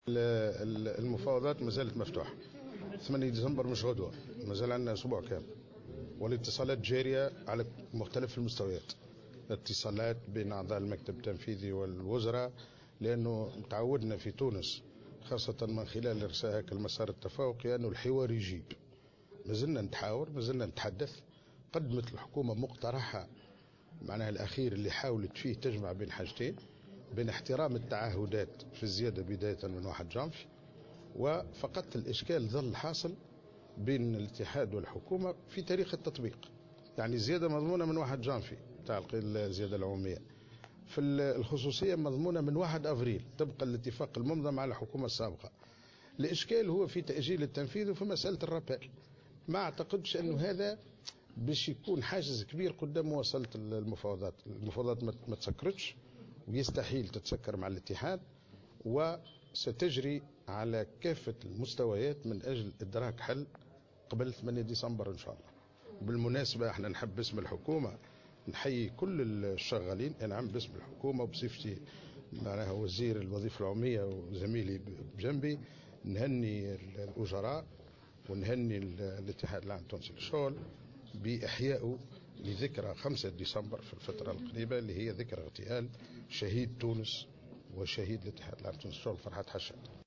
Dans une déclaration accordée à la correspondante de Jawhara FM, le ministre a affirmé que l'accord entre les deux parties portant sur les majorations, a déjà été conclu, et que le désaccord concerne uniquement la date de son application.